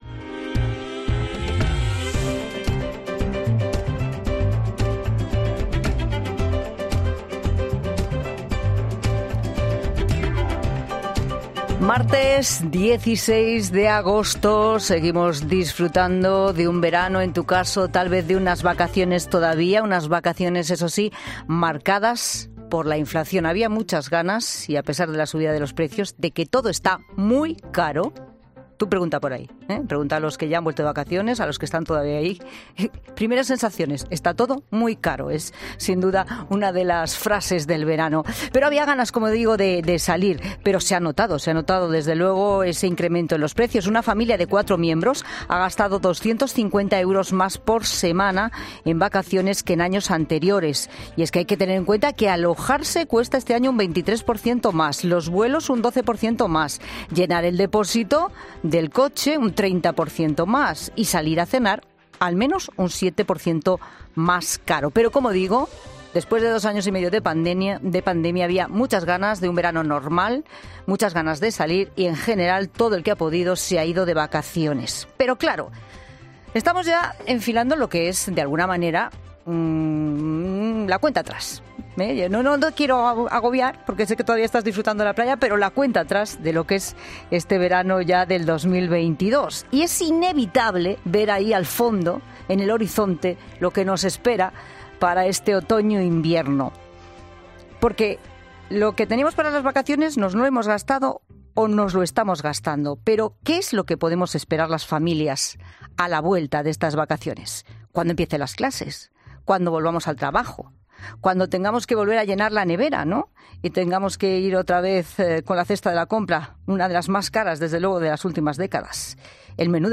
Hemos salido a la calle para ver si la gente tiene miedo a esa vuelta de vacaciones y a enfrentarse a un otoño marcado por el posible racionamiento de la energía y precios mucho más altos.